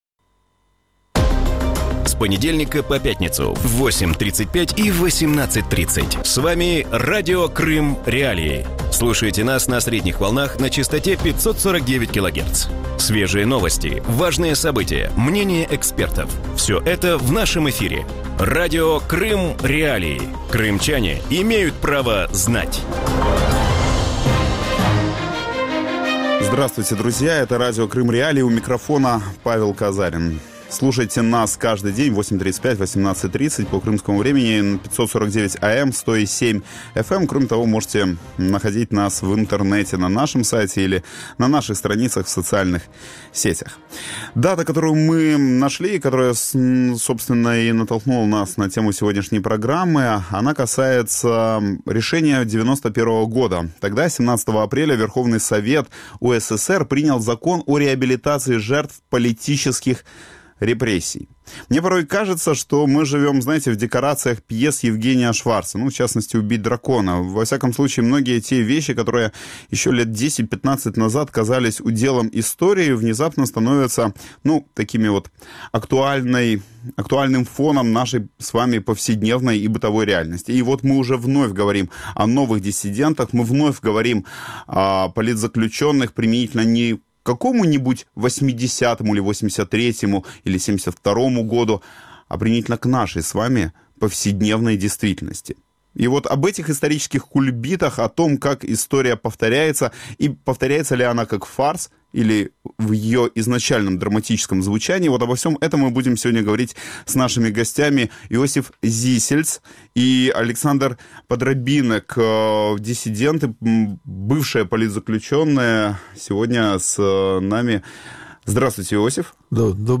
В годовщину реабилитации жертв политических репрессий в Украине в утреннем эфире Радио Крым.Реалии проводят параллели между ситуацией с правами человека в СССР и в современной России с аннексированным Крымом.